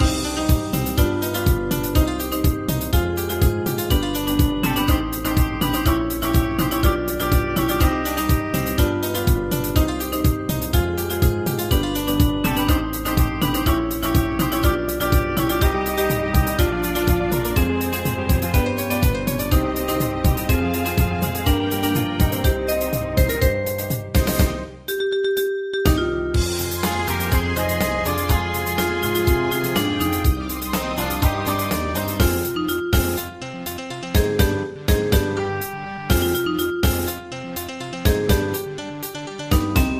大正琴の「楽譜、練習用の音」データのセットをダウンロードで『すぐに』お届け！
日本のポピュラー